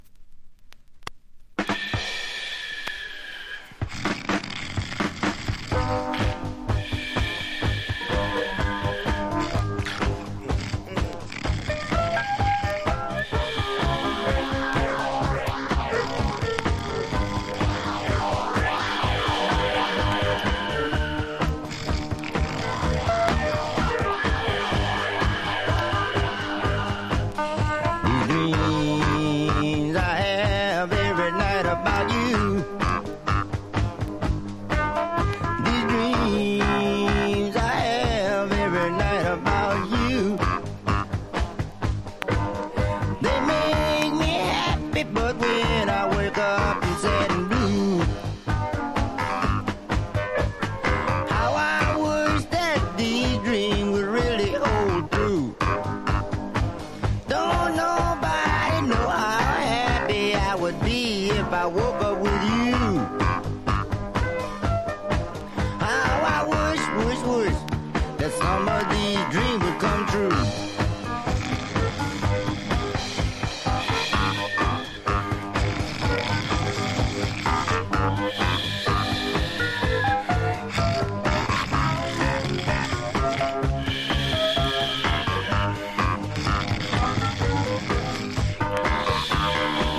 BLUES ROCK / SWAMP
シブいブルース・ロックとピロピロシンセが出会った稀代の名盤！